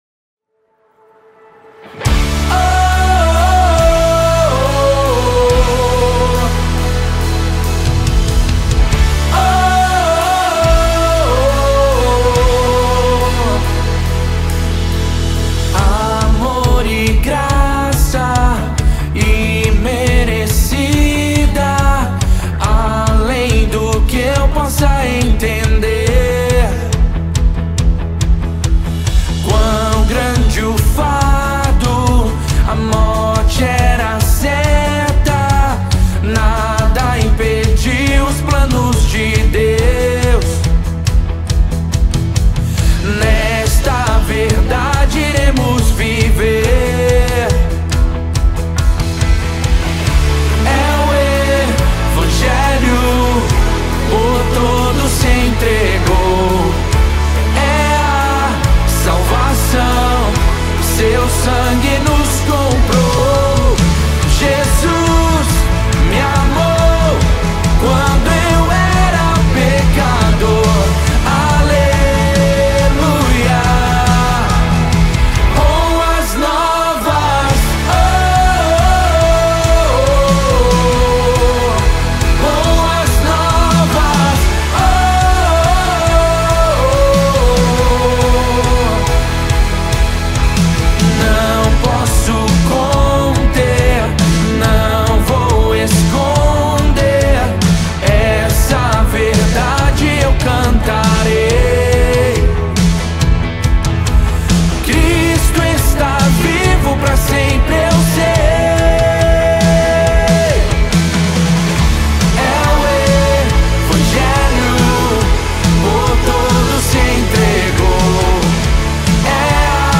24 просмотра 2 прослушивания 0 скачиваний BPM: 140